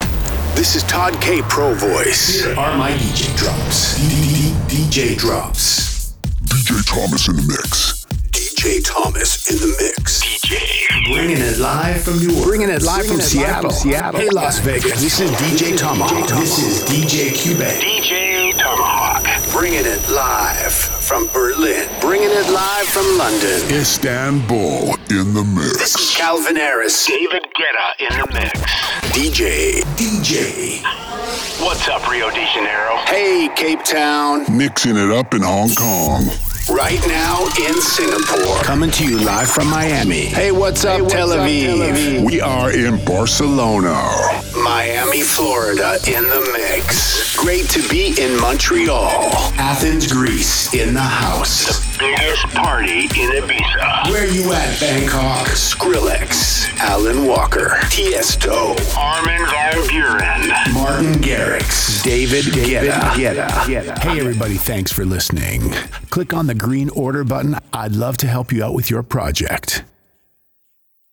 I have a calm, confident and reassuring voice for commercials or corporate reads and a wide range of Character voices for Video Games or Animation projects.
VOICE ACTOR DEMOS
0510DJ-Drops-Demo-for-Website.mp3